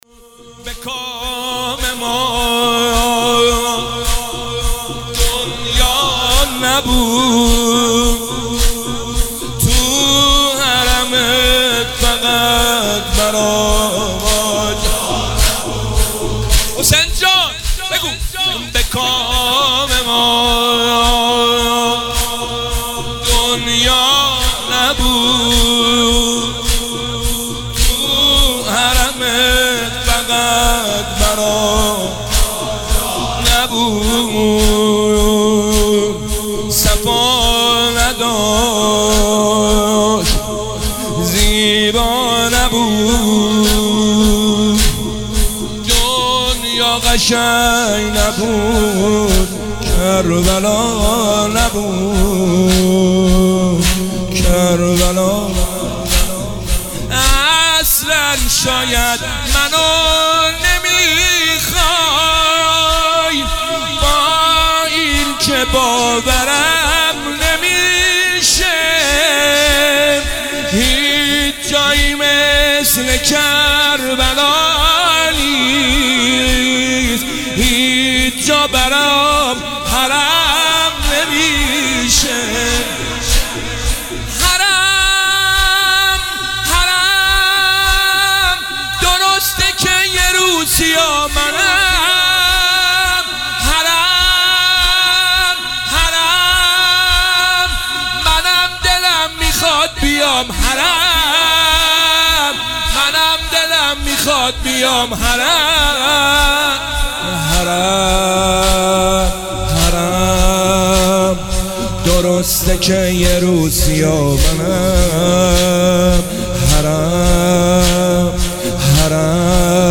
مداحی زمینه
شب 23 ماه رمضان 1446